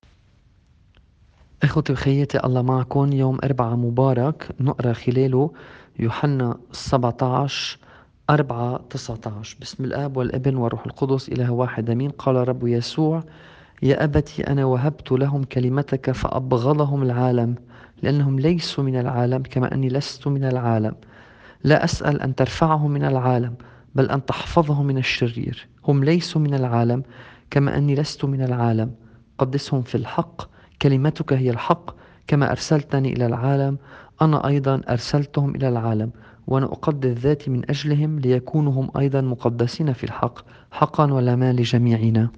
الإنجيل بحسب التقويم الماروني :